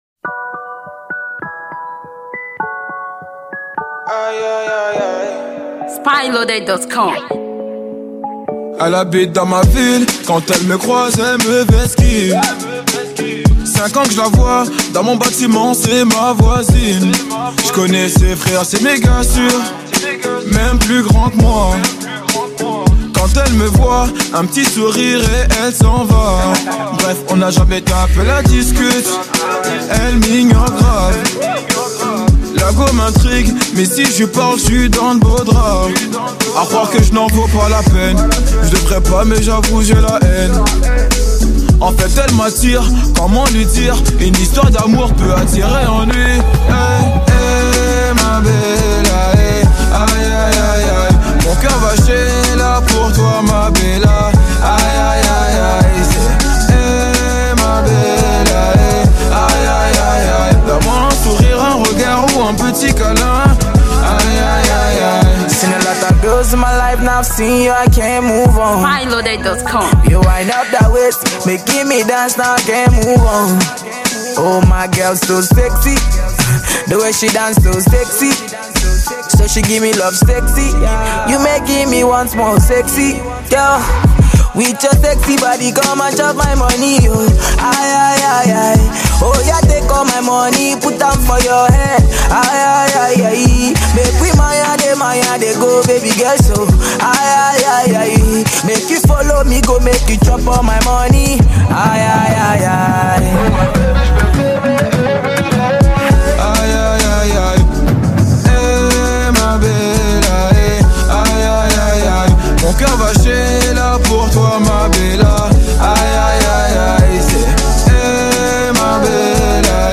French Afro Pop Singer